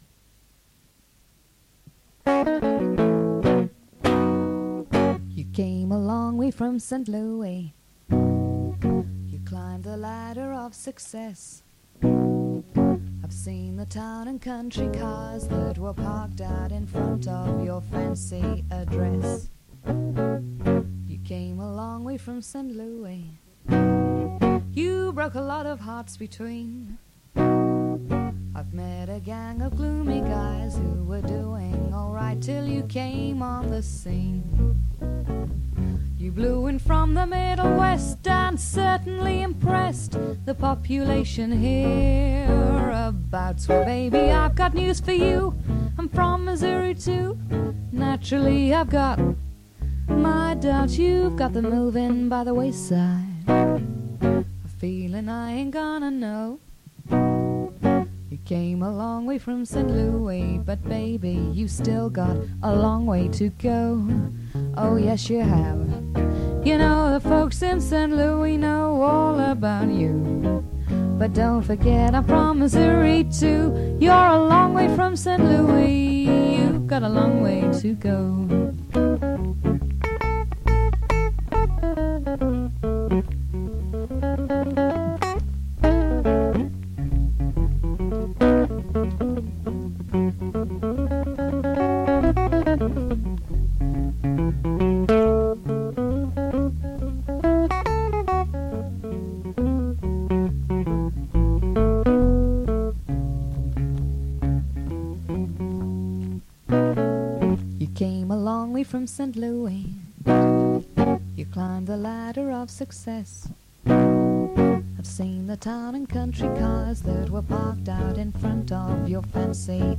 Vocal
guitar
Bass guitar). Recorded in my bedroom with direct line from guitars and Shure dynamic mike for vocals.